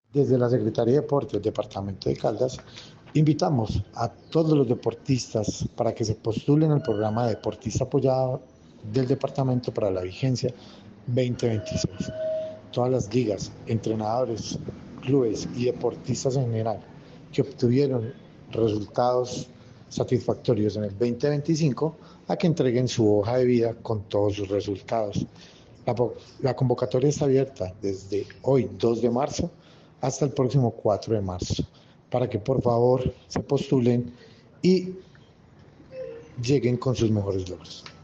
Juan Pablo Echeverry, secretario (e) de Deporte, Recreación y Actividad física de Caldas.